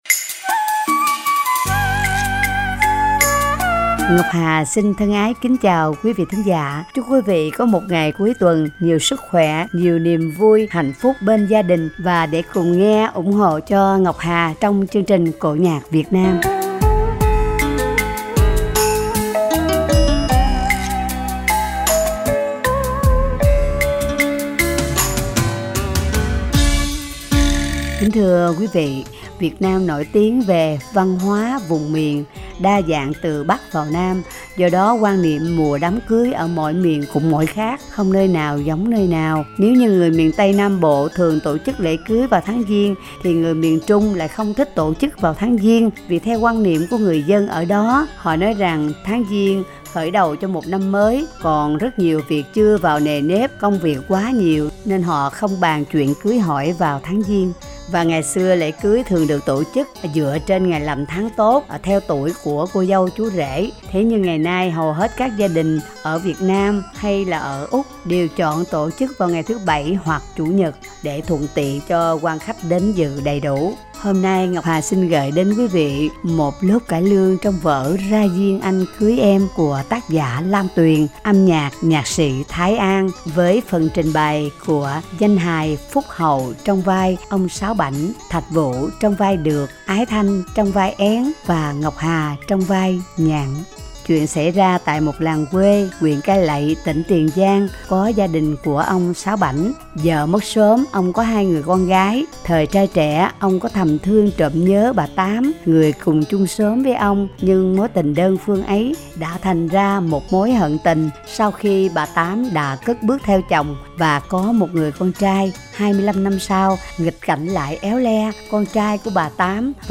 vở cải lương ngắn